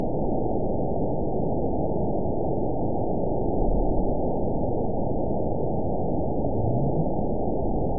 event 920445 date 03/25/24 time 19:56:58 GMT (1 year, 8 months ago) score 9.40 location TSS-AB01 detected by nrw target species NRW annotations +NRW Spectrogram: Frequency (kHz) vs. Time (s) audio not available .wav